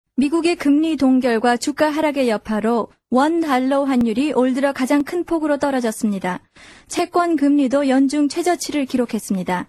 Sprecherin koreanisch für TV / Rundfunk / Industrie.
Sprechprobe: Industrie (Muttersprache):
Professionell voice over artist from Korea .